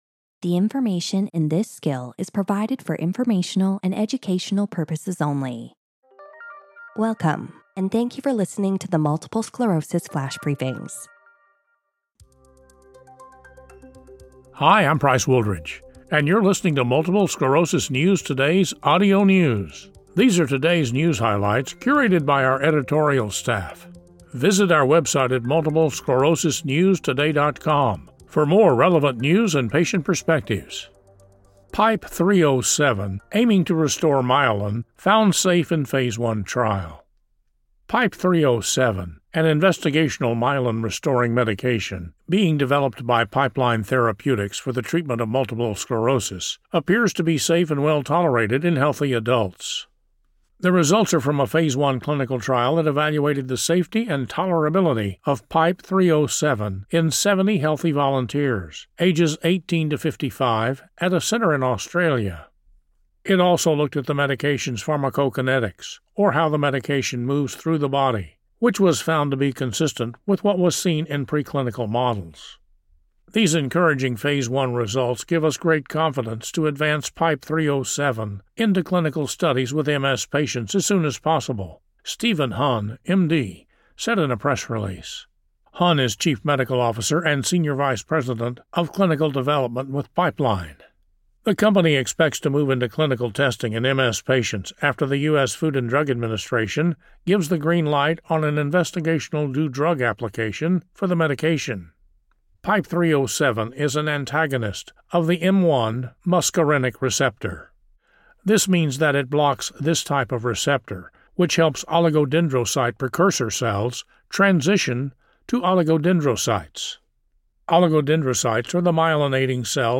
reads a news article about how PIPE-307, a myelin-restoring treatment, was found safe in a Phase 1 clinical trial in 70 healthy adults.